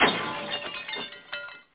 1 channel
shatter.mp3